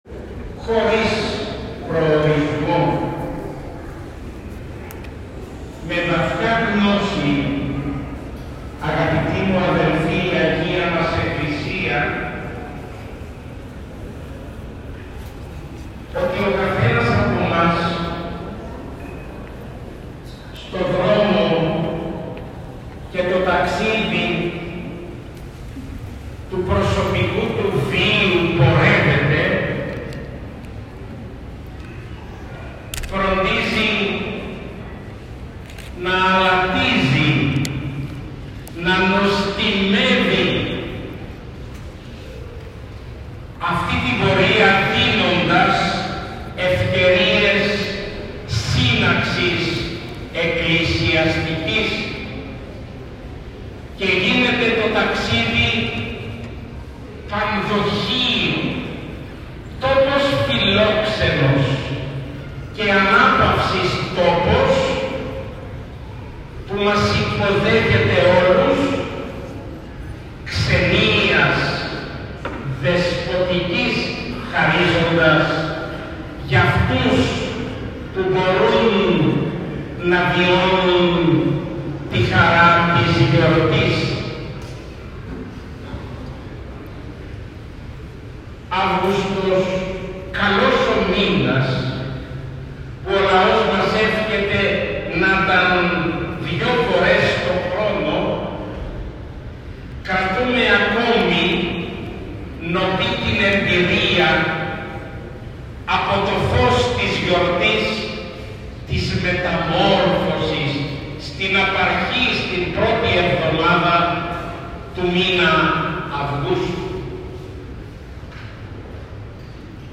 Στον πανηγυρίζοντα Ιερό Ναό Κοιμήσεως της Θεοτόκου στο Ωραιόκαστρο τελέστηκε την παραμονή της μεγάλης Θεομητορικής γιορτής Αρχιερατικός Εσπερινός χοροστατούντος του Σεβασμιωτάτου Μητροπολίτου Νεαπόλεως και Σταυρουπόλεως κ. Βαρνάβα.
Ακολουθεί ηχητικό αρχείο με το κήρυγμα του Σεβασμιωτάτου: